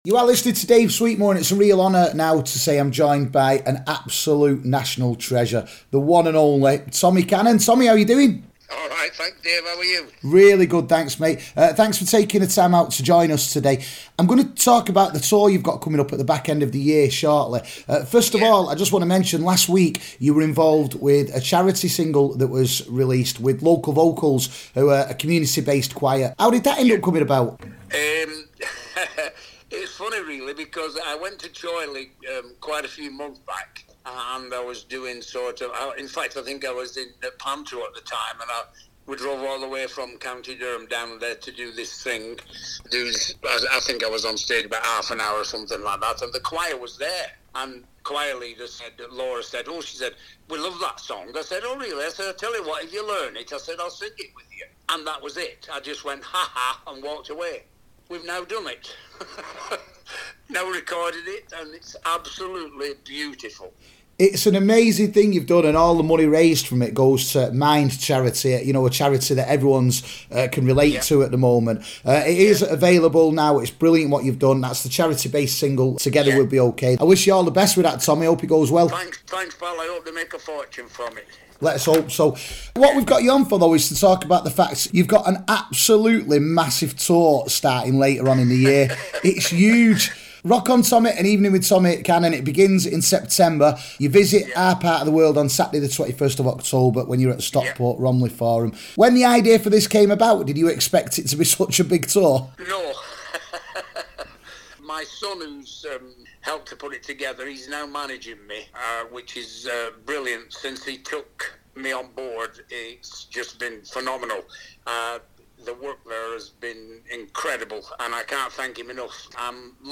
LISTEN: Exclusive interview with Tommy Cannon